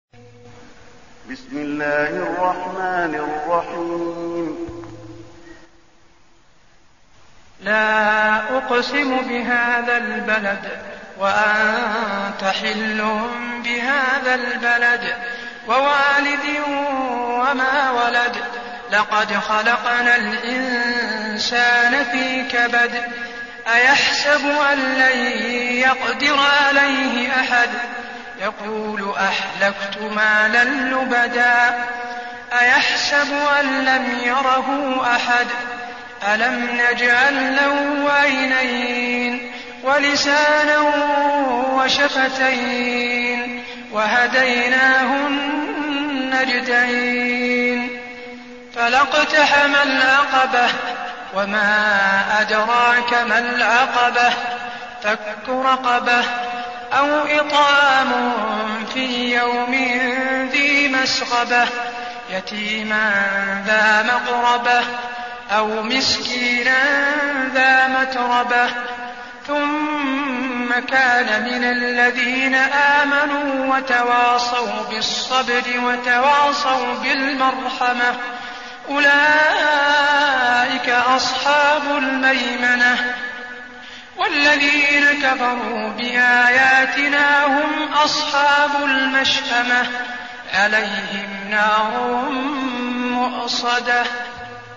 المكان: المسجد النبوي البلد